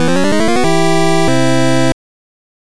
snd_logo.wav